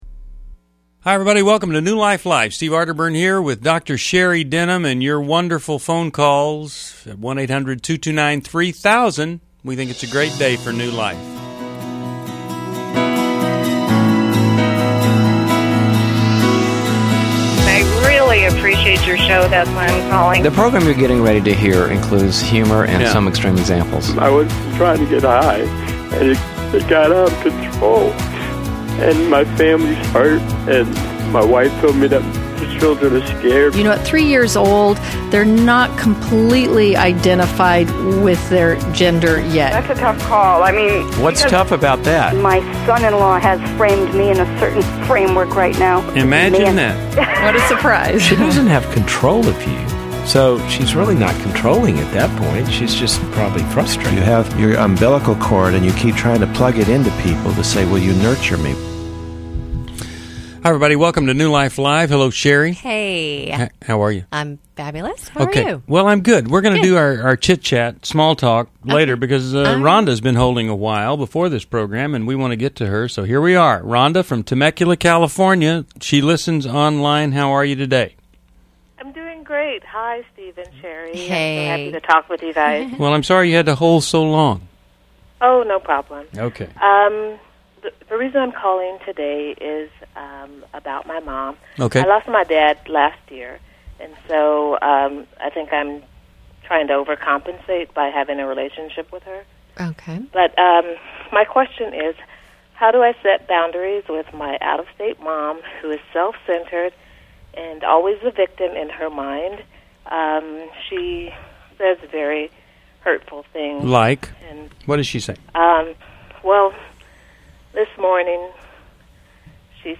Caller Questions: 1. What boundaries can I set with my self-centered mother? 2.